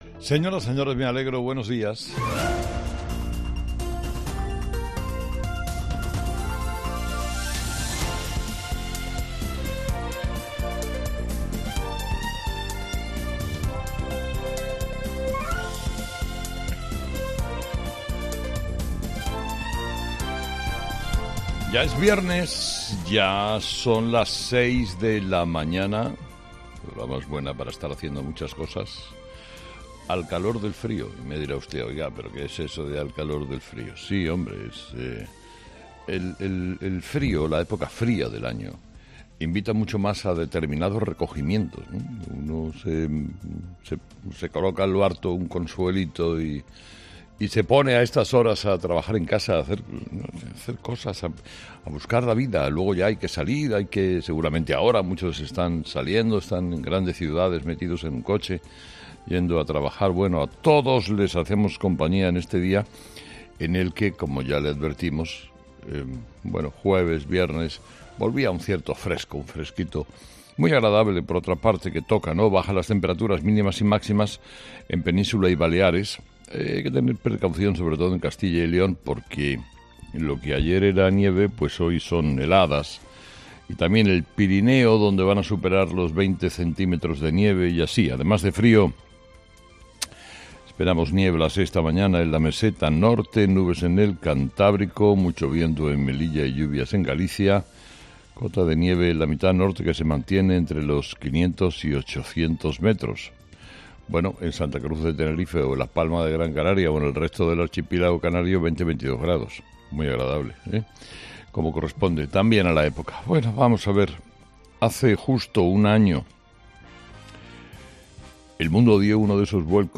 Carlos Herrera, director y presentador de 'Herrera en COPE', comienza el programa de este jueves analizando las principales claves de la jornada, que pasan, entre otros asuntos, porque este 24 de febrero se cumple un año del momento en el que Rusia comenzó la invasión de Ucrania con ese balance y la sensación de que el conflicto no va a terminar a corto plazo.